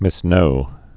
(mĭs-nō)